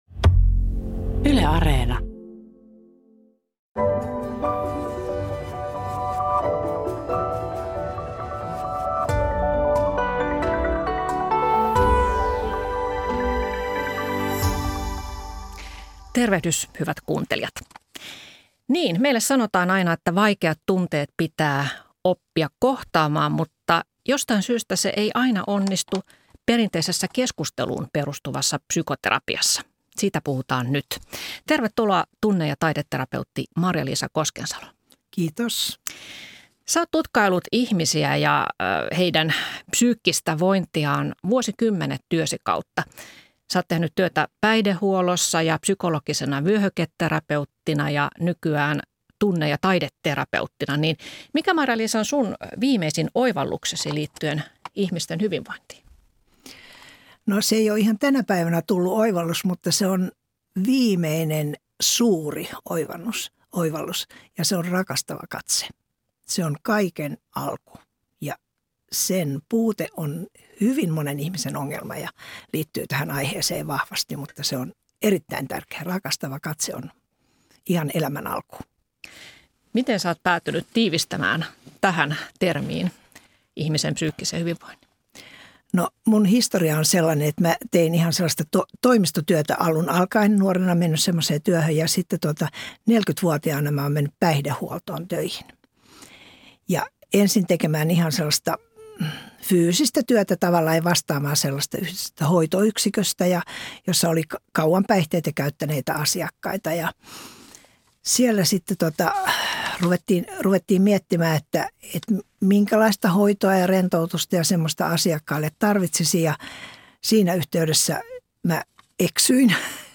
Missä vika, jos keskusteluun perustuva terapia ei vie ahdistusta pois? Keskustelijoina psykoterapeutti ja psykiatri